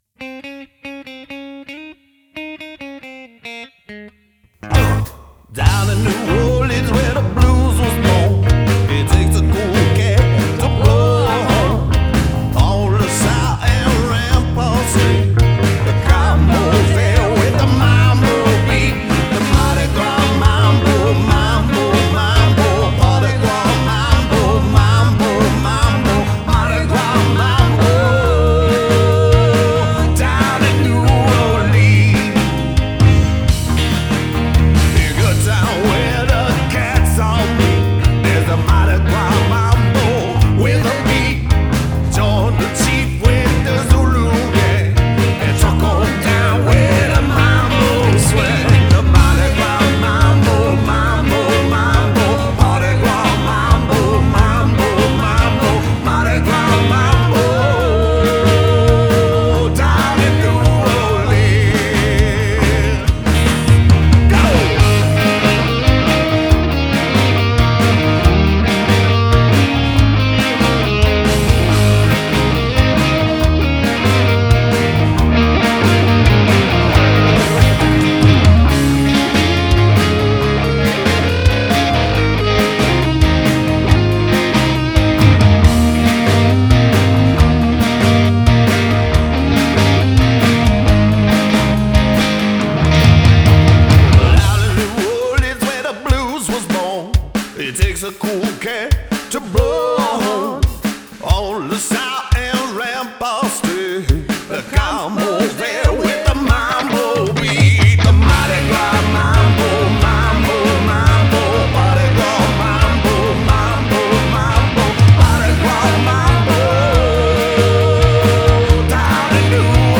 guitar + vocals
bass + vocals
drums